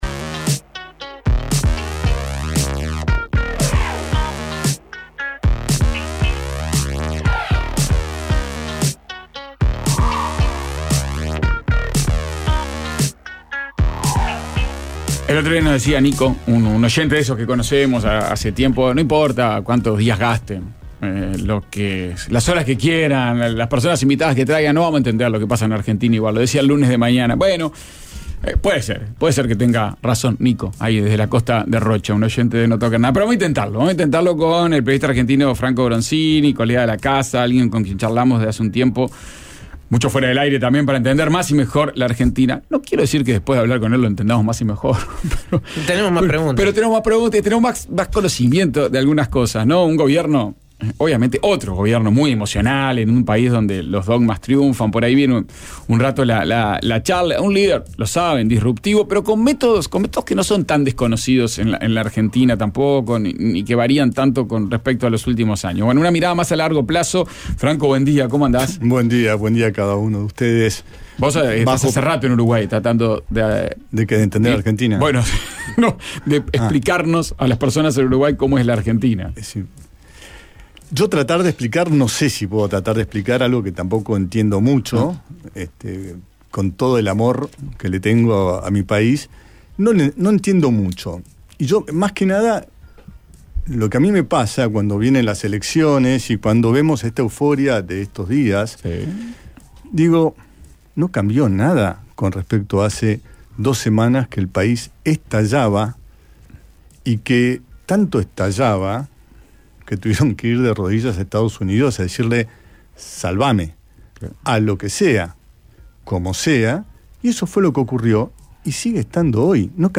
Música en vivo y lecciones de guitarra